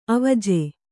♪ avaje